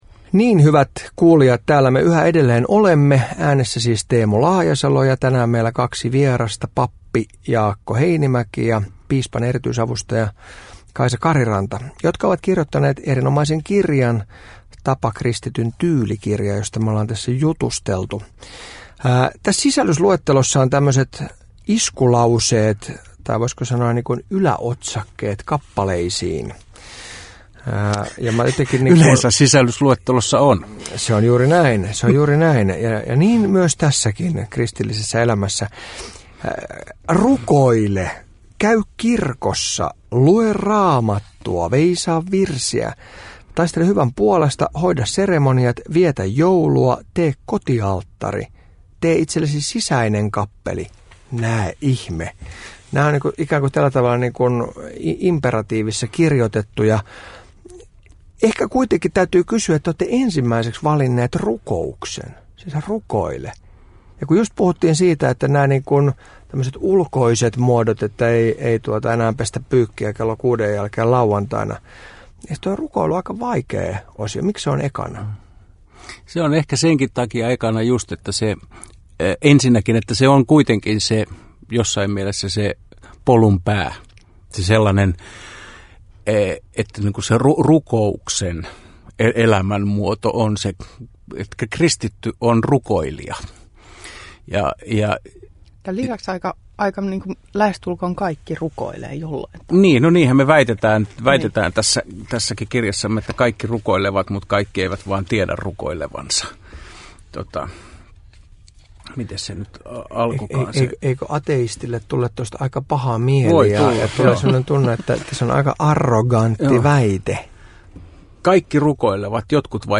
He keskustelevat Laajasalon kanssa muun muassa lepopäivän pyhittämisestä.